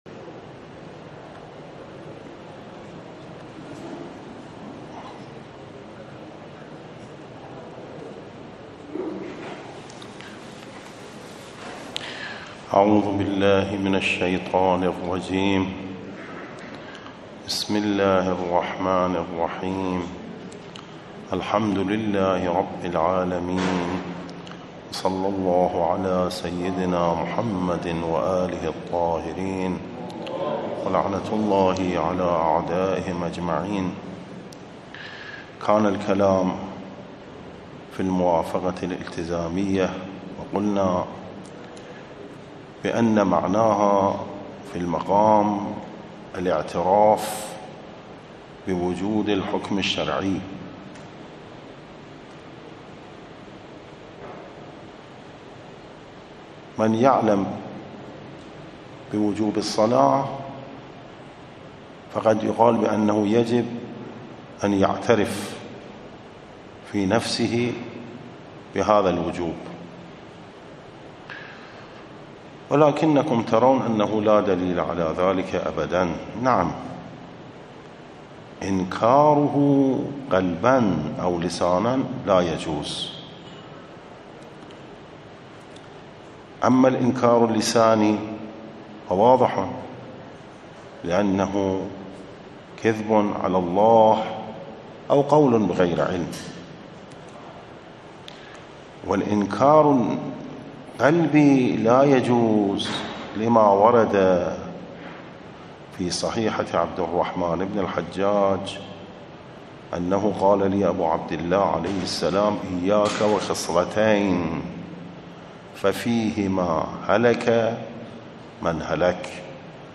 نصّ الدّرس 18 ، السبت 12 ربیع الثانی 1445